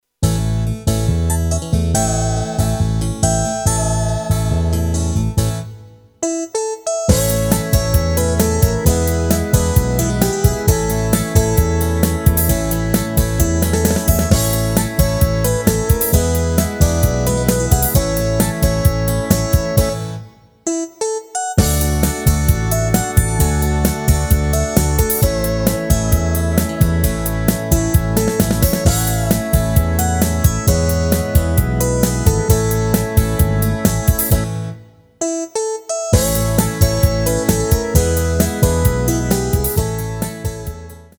Rubrika: Pop, rock, beat
(Instrumentální skladba)